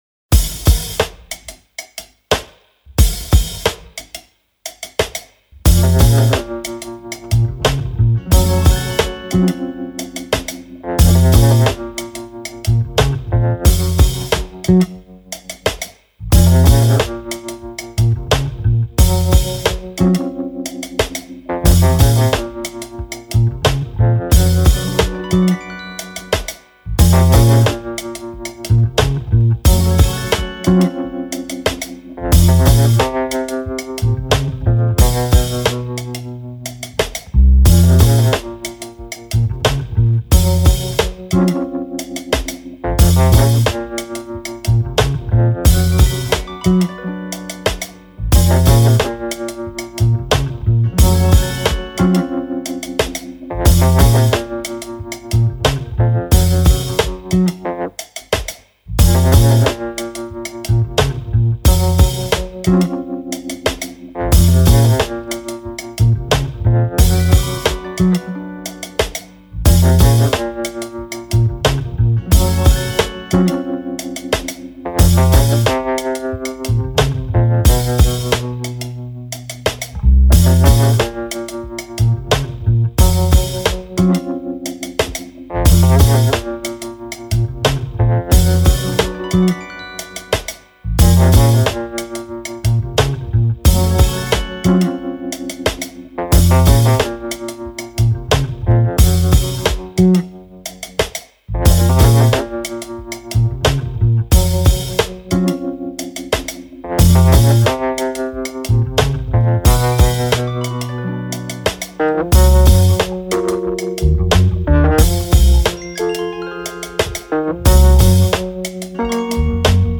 Groovy spy and agent hip hop beat.